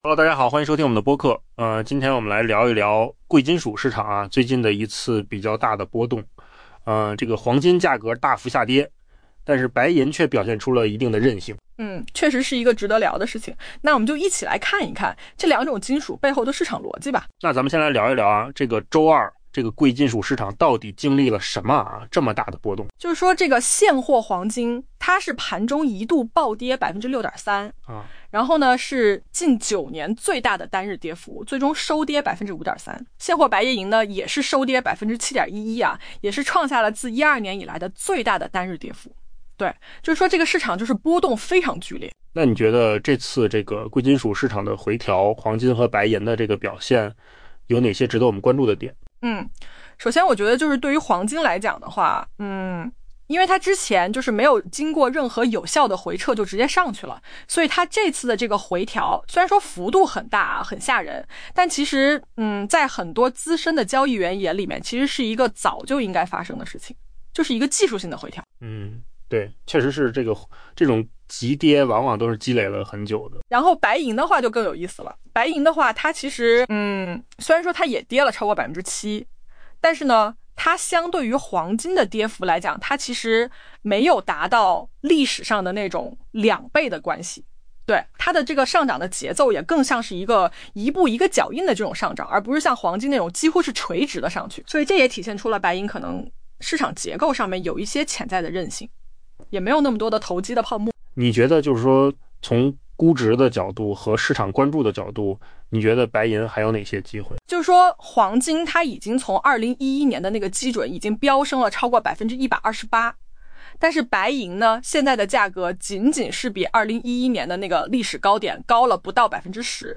AI 播客：换个方式听新闻 下载 mp3 音频由扣子空间生成 贵金属市场周二遭遇剧烈回调。